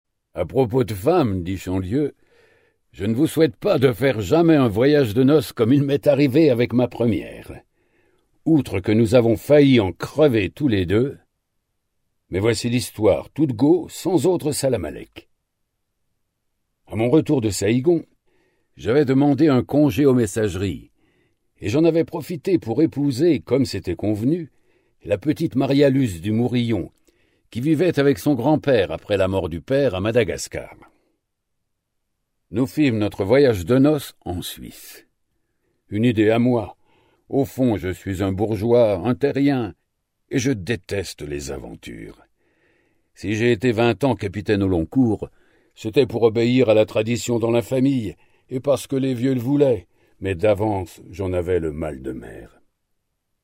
Une ambiance de film d'horreur pleinement restituée par l'interprétation habitée et la voix impressionnante